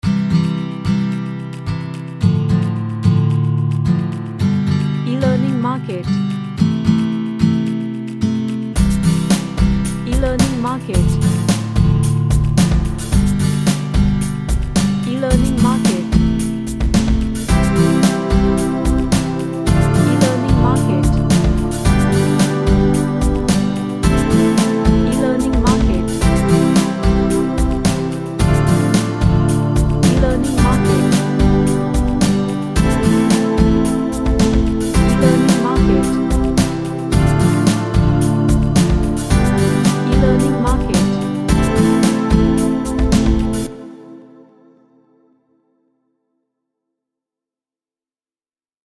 An Acoustic genre track featuring guitar melody.
Happy